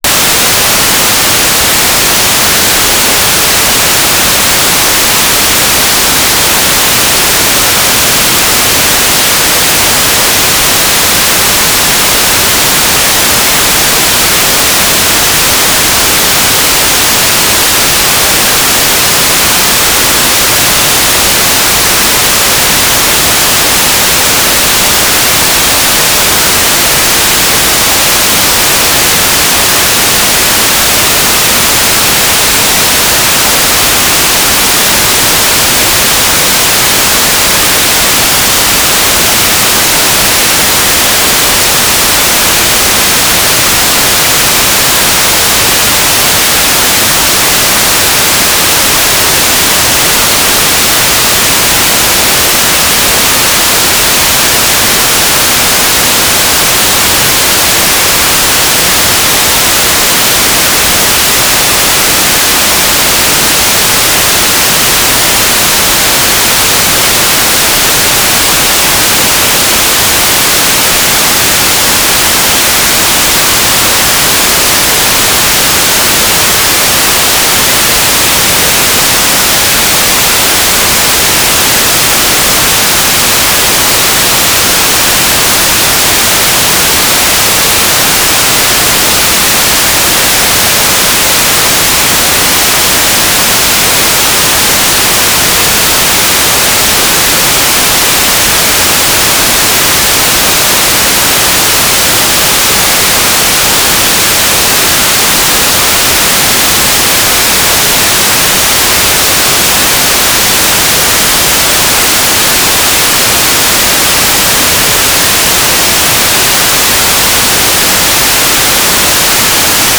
"transmitter_description": "Mode U - Transmitter",
"transmitter_mode": "FM",